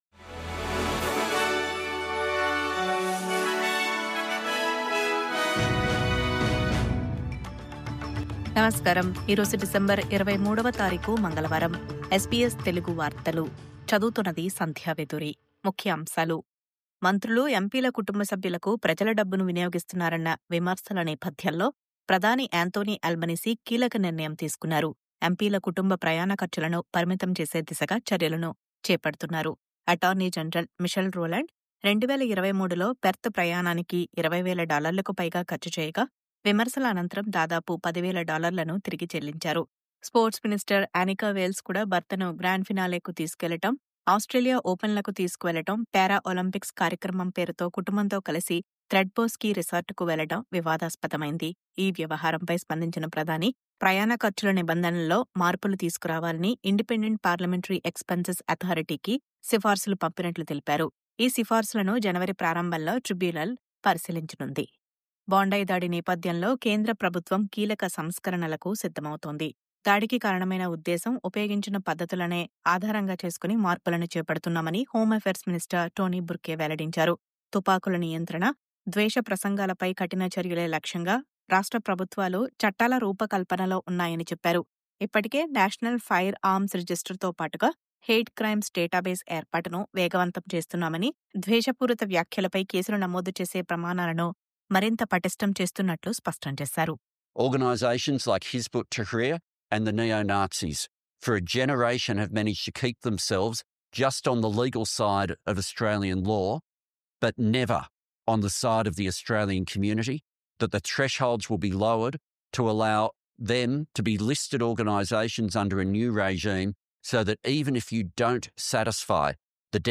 News update: ప్రజాధనం దుర్వినియోగంపై విమర్శల నేపథ్యంలో MPల ప్రయాణ సౌకర్యాలపై ప్రధాని ఆల్బనీసీ కీలక నిర్ణయం...